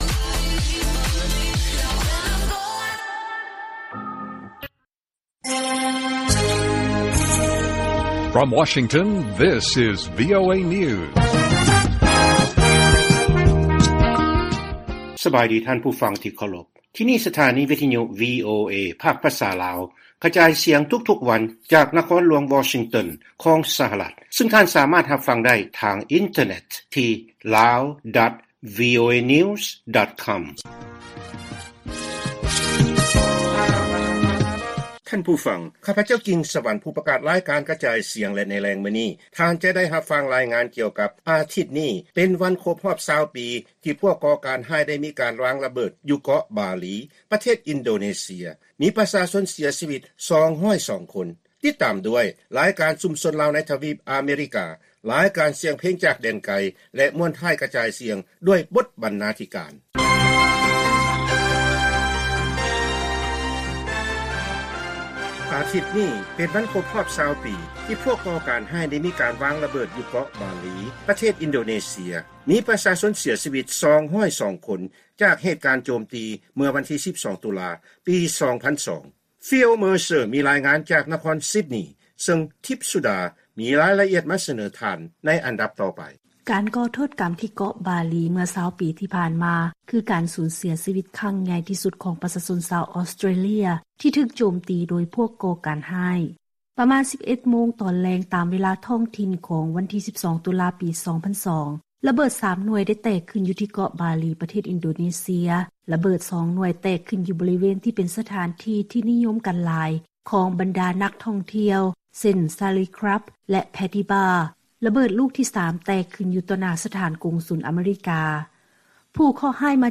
ລາຍການກະຈາຍສຽງຂອງວີໂອເອລາວ
ວີໂອເອພາກພາສາລາວ ກະຈາຍສຽງທຸກໆວັນ ສຳລັບແລງມື້ນີ້ ເຮົາມີ